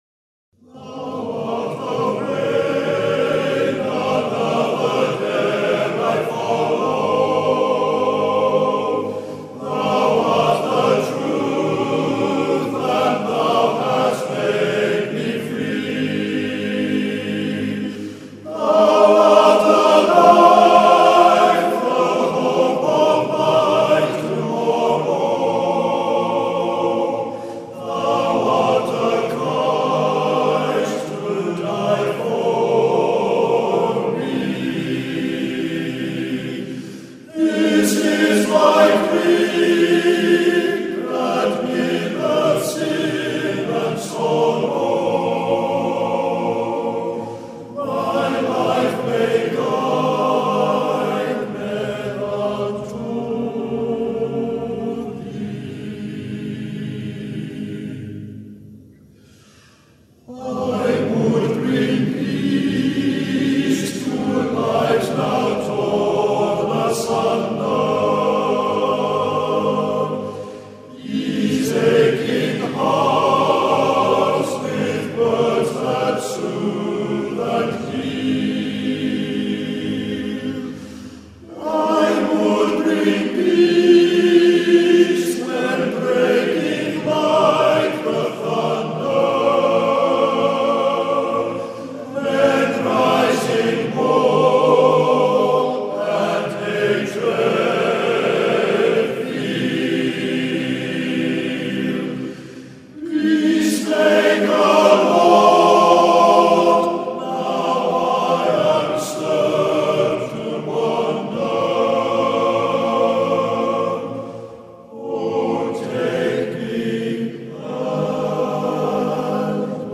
Key: E♭
Tempo: 72